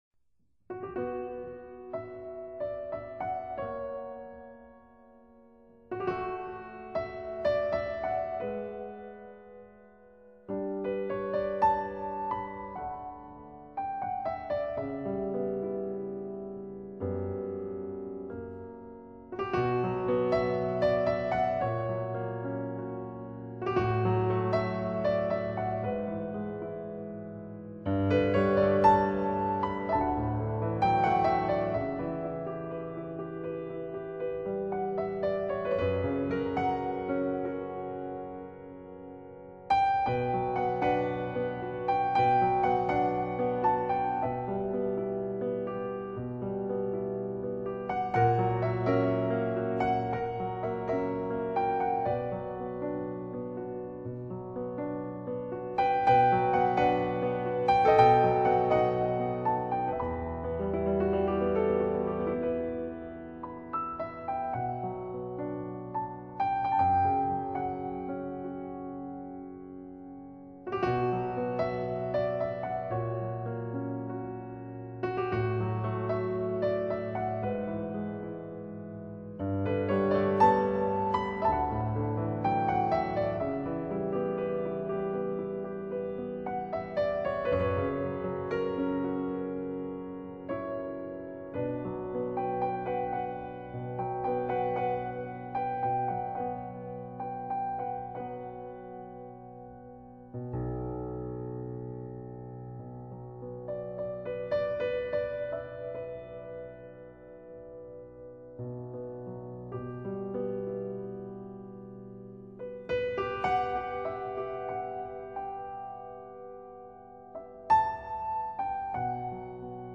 爵士女钢琴家
接下来将以四首纯钢琴作品，回归到New Age曲风上。
不难听出其中的寂静与孤独。